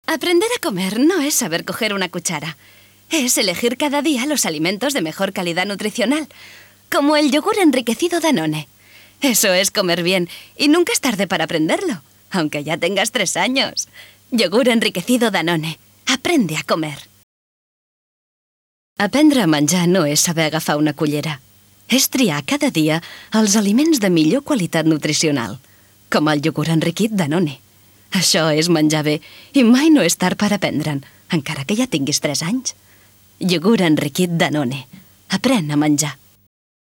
Sprecherin spanisch für Werbung, Industrie, Imagefilme, e-learning ua.
Kein Dialekt
Sprechprobe: Industrie (Muttersprache):
spanish female voice over artist